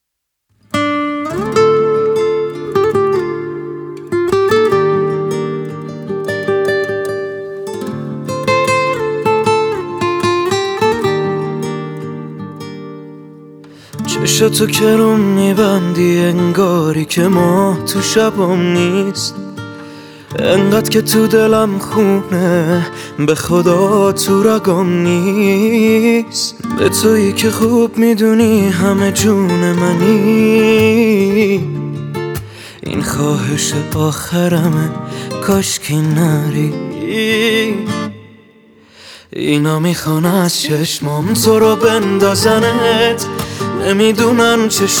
موسیقی پاپ فارسی